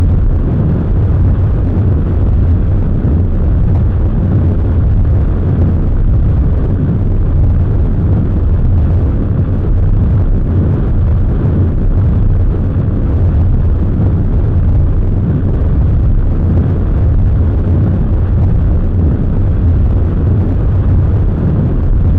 thrust5.mp3